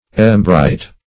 Embright \Em*bright"\